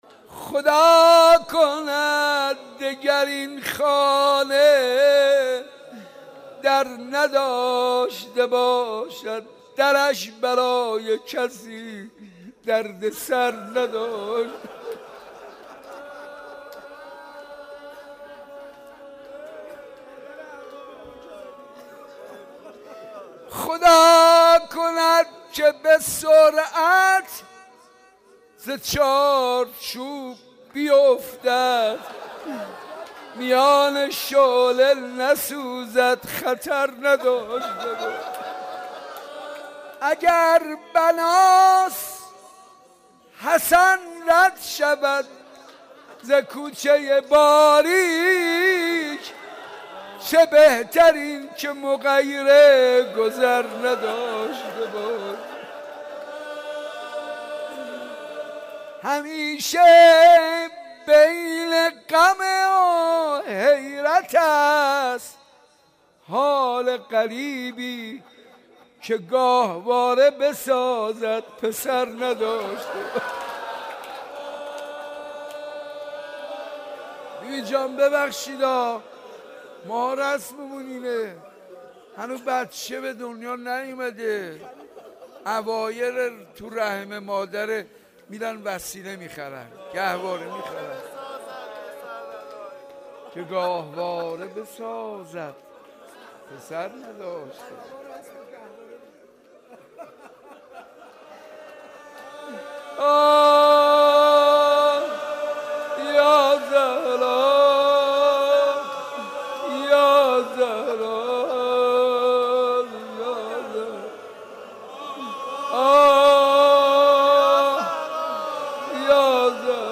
حسینیه ی صنف لباس فروش ها
روضه ی حضرت زهرا سلام الله علیها